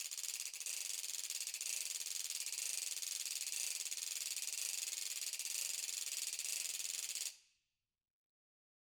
Ratchet1-Slow_v1_rr1_Sum.wav